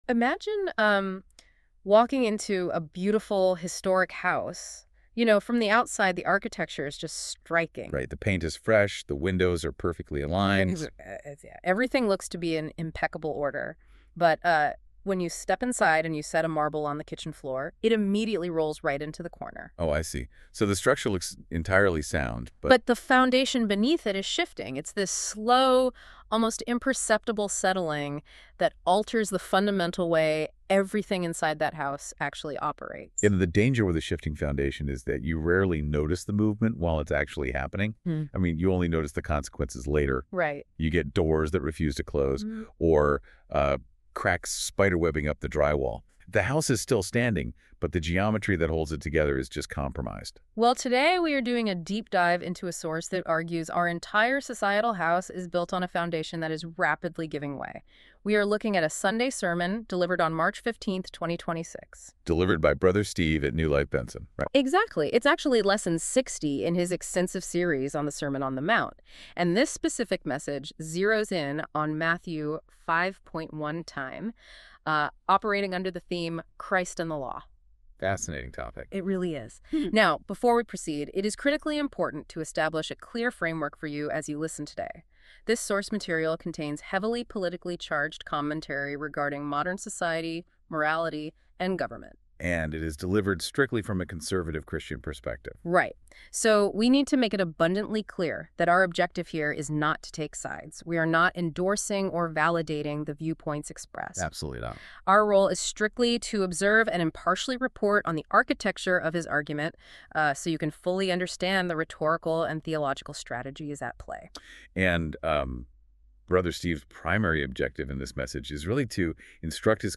Sermons | NEW LIFE FAMILY WORSHIP CENTER